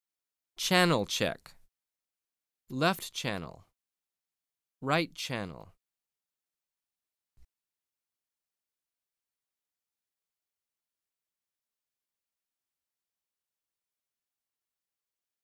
Track 01- Channel Check.wav